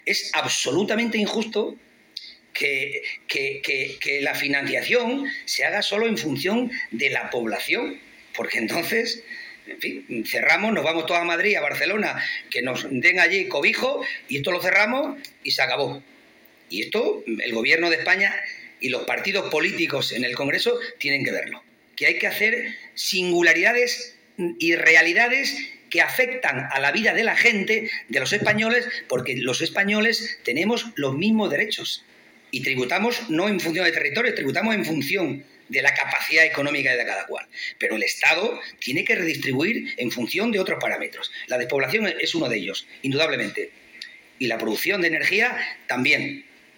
Miguel Ángel Morales inaugura el II Foro de Municipalismo, en el que defiende el papel de alcaldes y alcaldesas, “que asumen competencias que no les corresponden, por lo que hay que clarificar la financiación”
CORTES DE VOZ